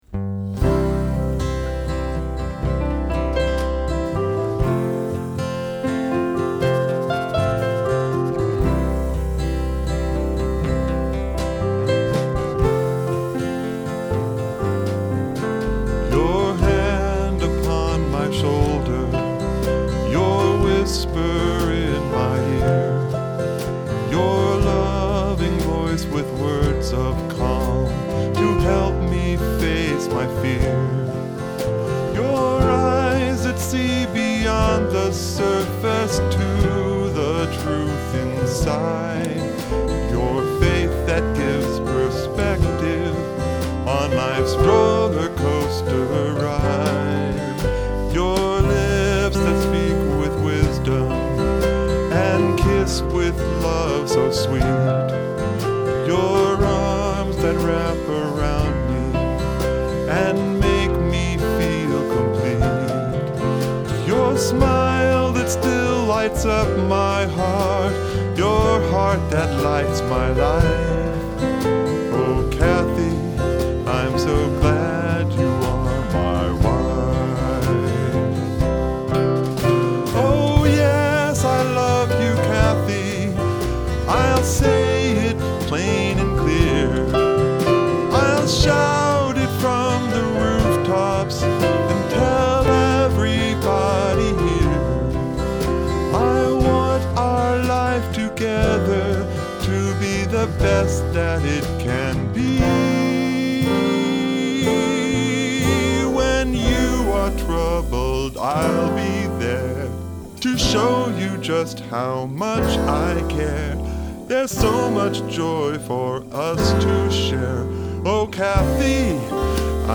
Guitars
Drums
Piano, bass &